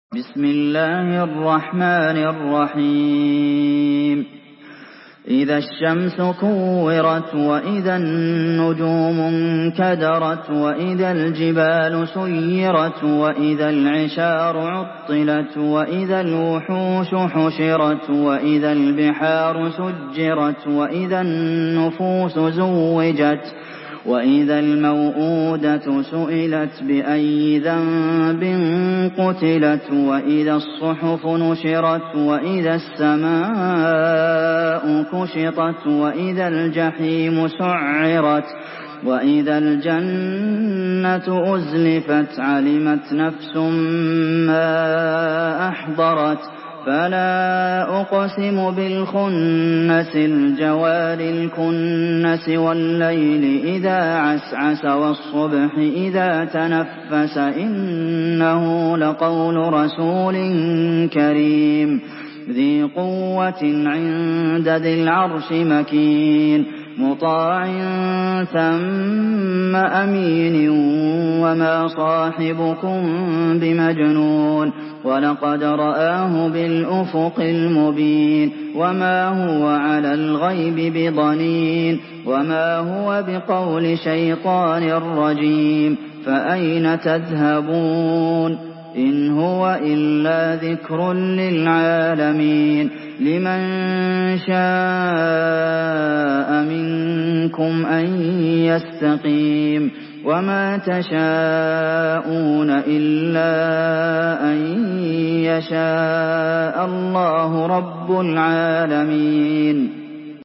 Surah At-Takwir MP3 in the Voice of Abdulmohsen Al Qasim in Hafs Narration
Murattal